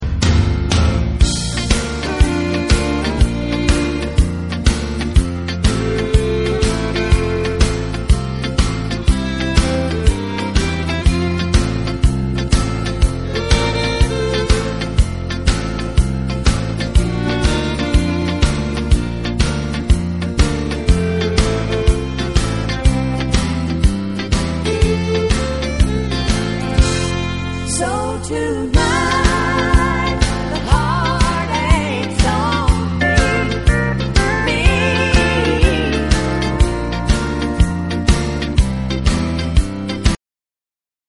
Karaoke lyrics and music will appear on your screen.